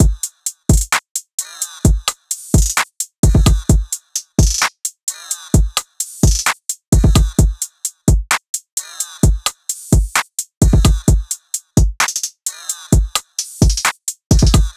SOUTHSIDE_beat_loop_trill_full_02_130.wav